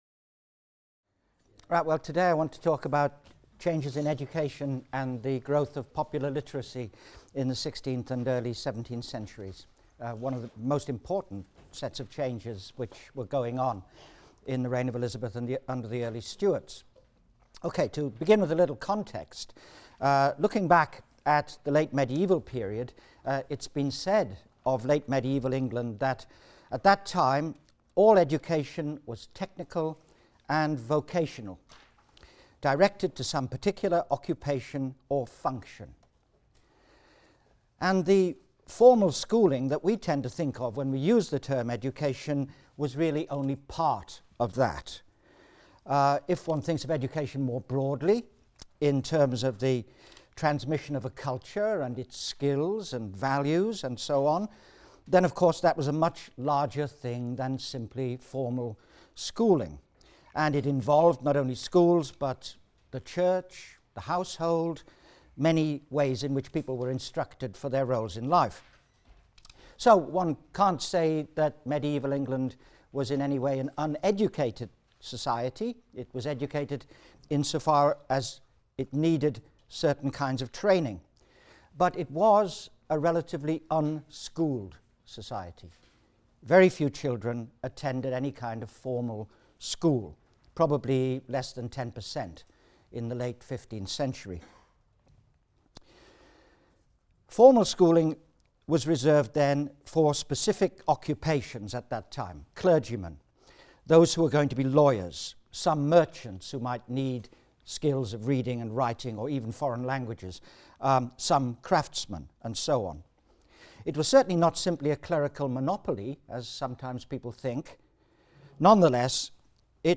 HIST 251 - Lecture 17 - Education and Literacy | Open Yale Courses